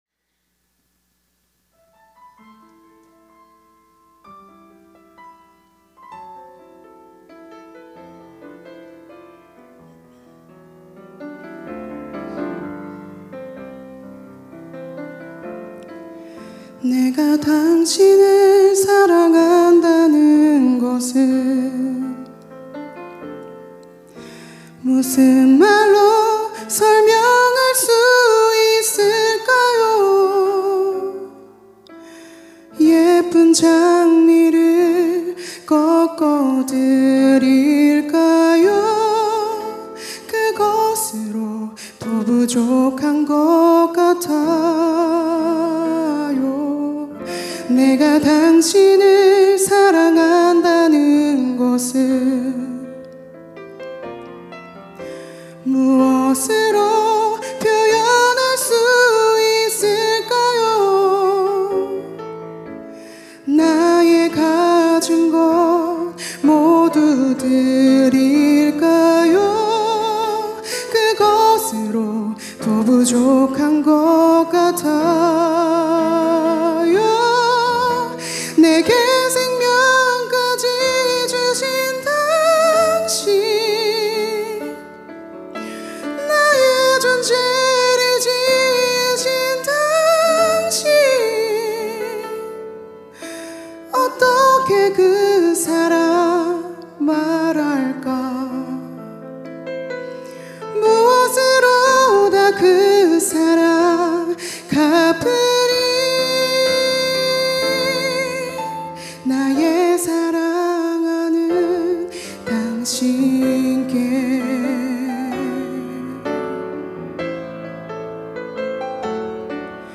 특송과 특주 - 장미